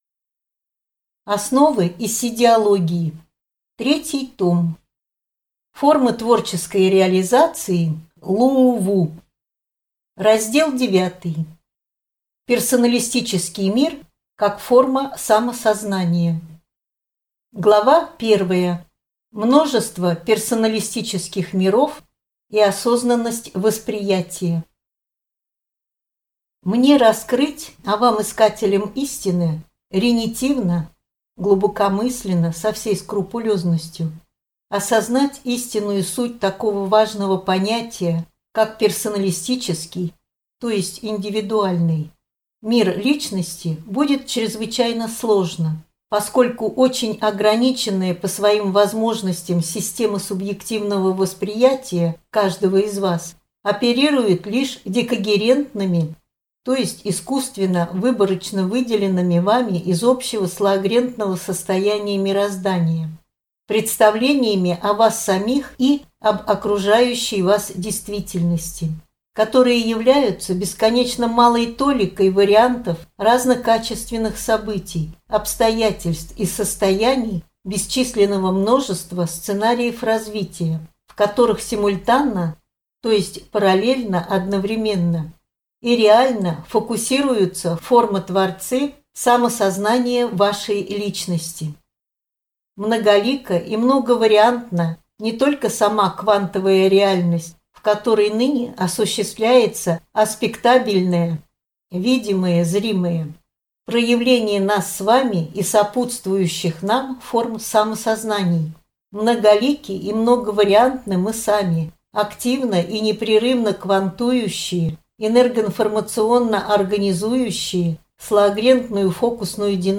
Аудиокнига Ииссиидиология. Основы. Том 3 | Библиотека аудиокниг